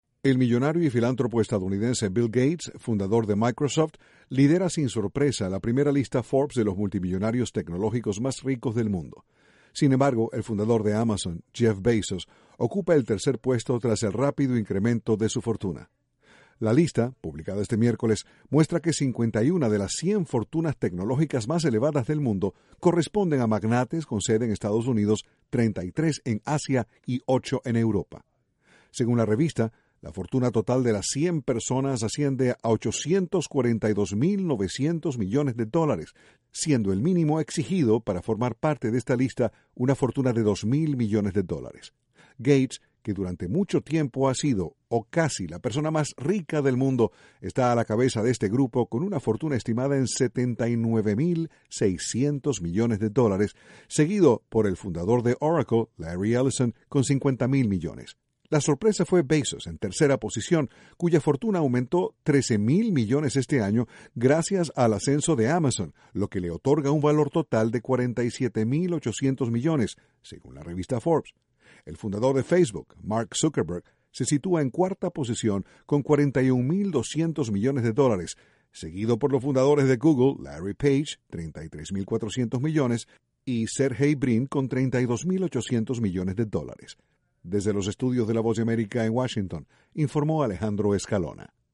VOA: Nota Económica: Jeff Bezos